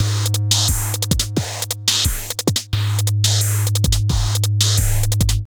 ___SINELOOP 2.wav